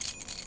R - Foley 12.wav